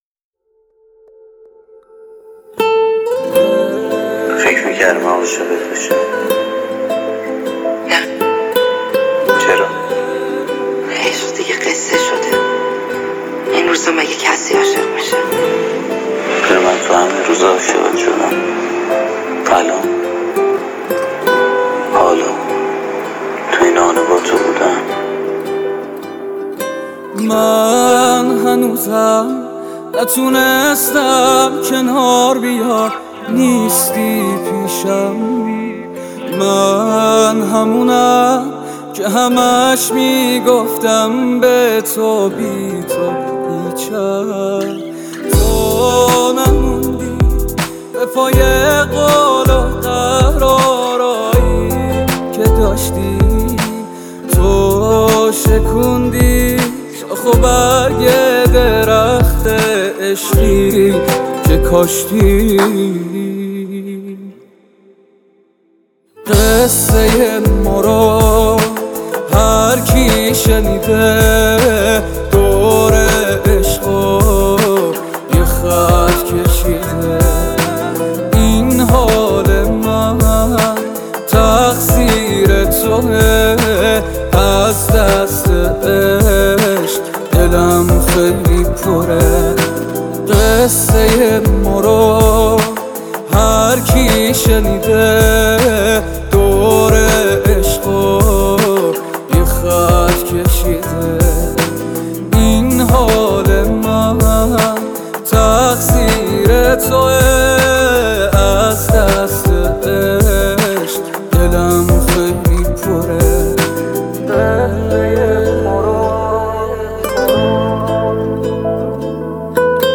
موزیک ایرانی